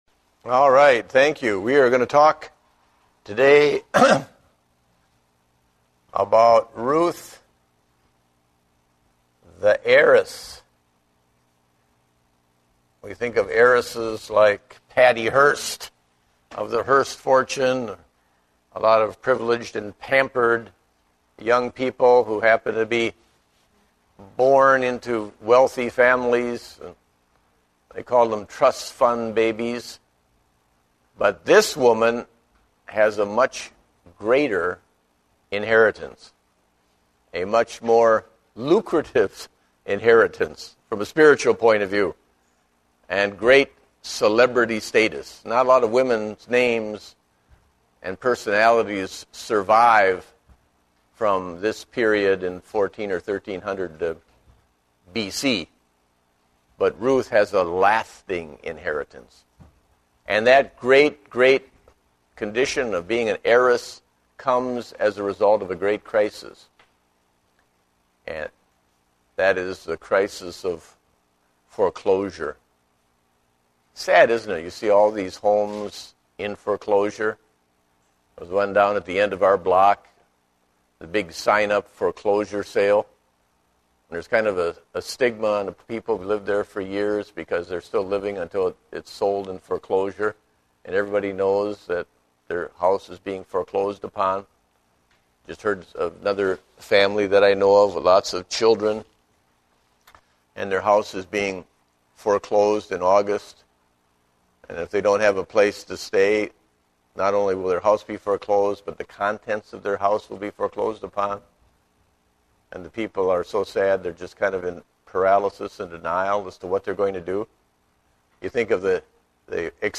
Date: July 25, 2010 (Adult Sunday School)